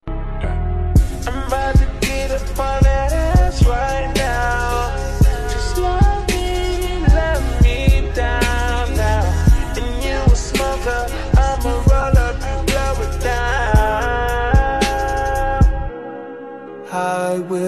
Class 66 60mph Freight liner sound effects free download
Diesel-hauled engineering train passing through ponty-y-clun with tones